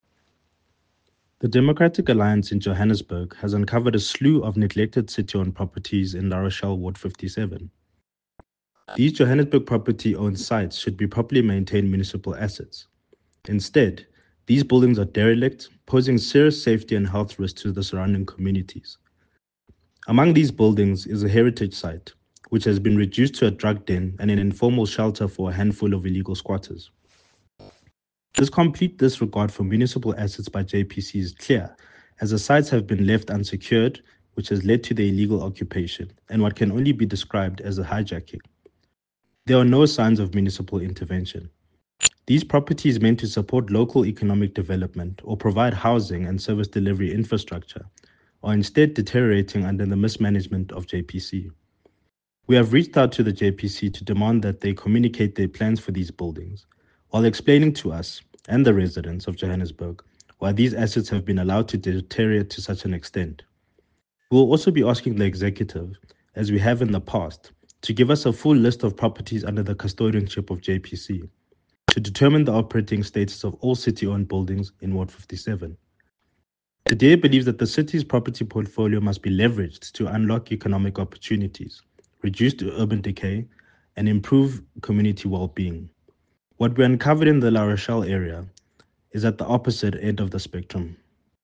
Issued by Cllr Bongani Nkomo – DA Johannesburg Shadow MMC for Economic Development
Note to Editors: Please find an English soundbite by Cllr Bongani Nkomo